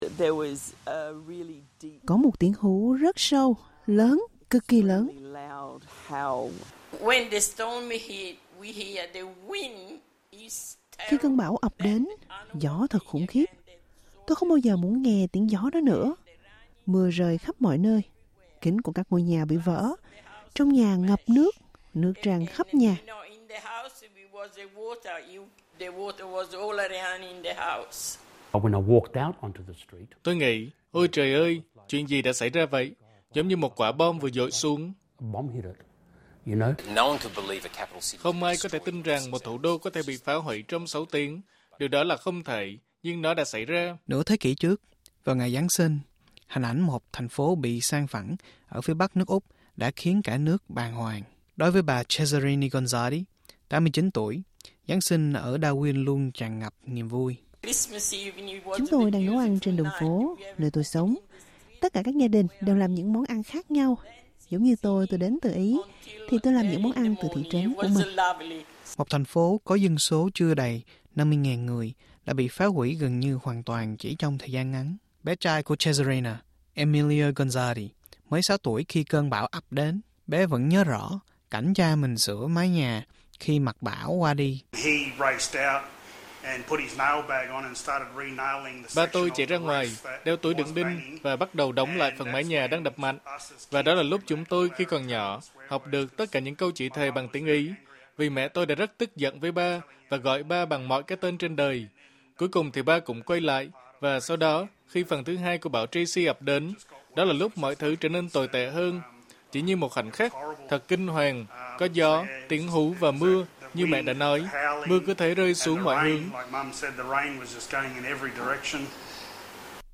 SBS đã nói chuyện với những người sống sót sau cơn bão Tracy. Họ nói rằng 50 năm trôi qua, ký ức về Giáng sinh năm 1974 vẫn còn nguyên vẹn.